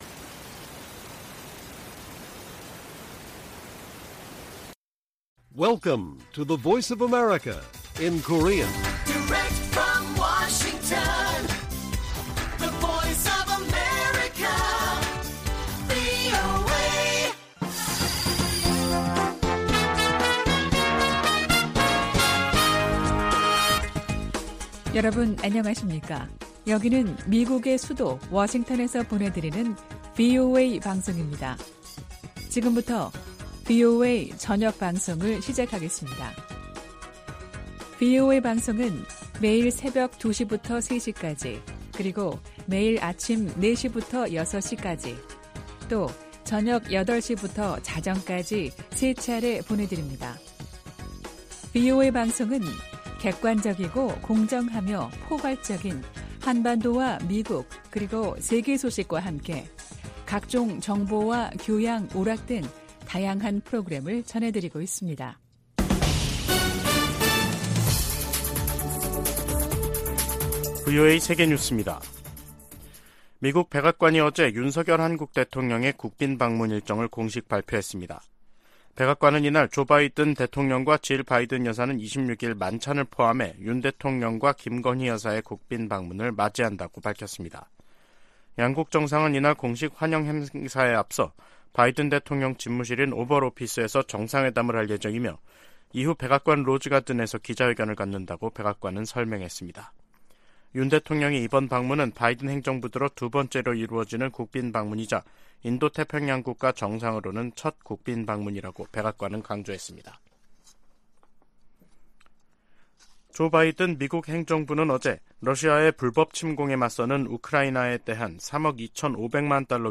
VOA 한국어 간판 뉴스 프로그램 '뉴스 투데이', 2023년 4월 20일 1부 방송입니다. 백악관은 윤석열 한국 대통령의 방미 일정을 밝히고, 26일 오벌 오피스에서 조 바이든 대통령과 회담한다고 발표했습니다. 미 국무부는 북한의 불법적인 대량살상무기(WMD) 개발 자금을 계속 차단할 것이라고 밝혔습니다.